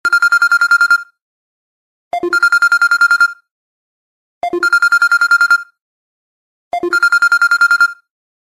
короткие